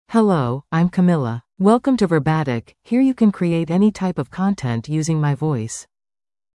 FemaleEnglish (United States)
CamilaFemale English AI voice
Camila is a female AI voice for English (United States).
Voice sample
Listen to Camila's female English voice.
Camila delivers clear pronunciation with authentic United States English intonation, making your content sound professionally produced.